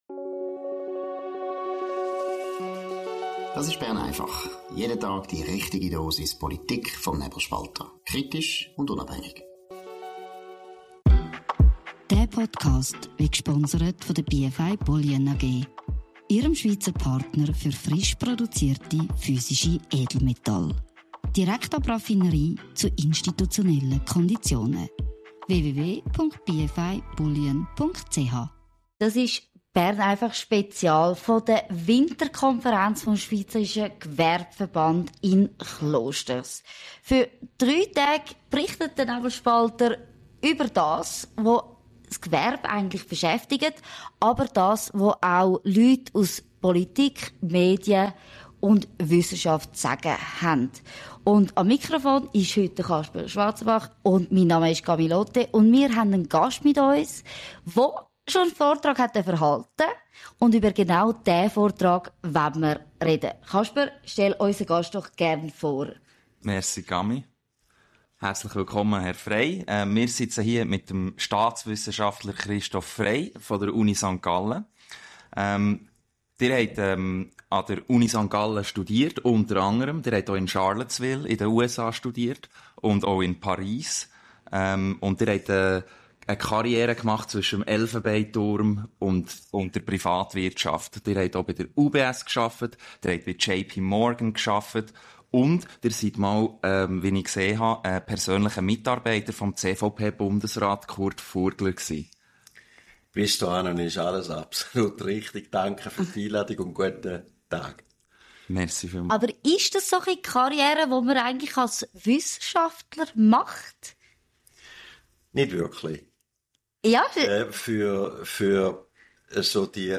Seit 1949 findet in Klosters jeweils Mitte Januar die Gewerbliche Winterkonferenz des Schweizerischen Gewerbeverbands statt.